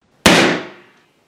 Bullet Hit Metal
bullet-hit bullet-hit-metal gun gun-shot gunshot gun-shot-hit gun-shot-hit-metal sound effect free sound royalty free Memes